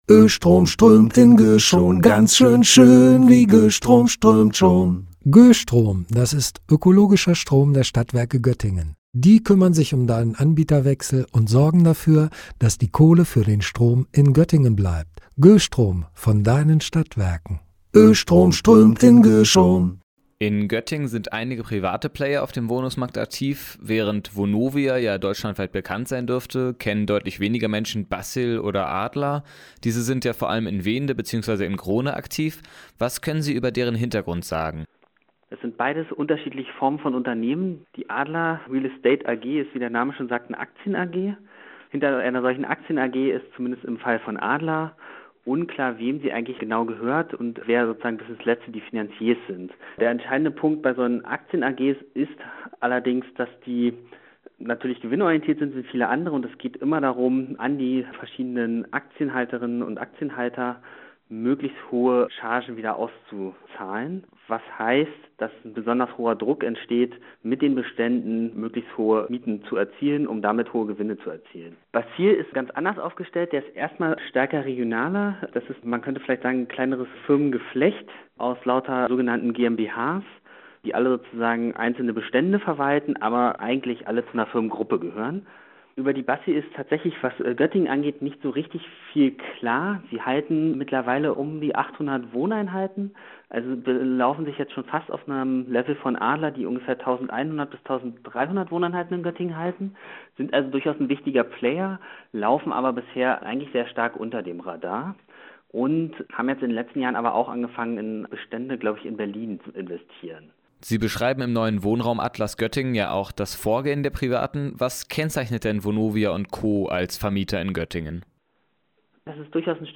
Beiträge > Vonovia, Adler, Bassil: Neuer Wohnraum Atlas Göttingen veröffentlicht - StadtRadio Göttingen